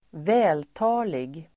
Uttal: [²v'ä:lta:lig]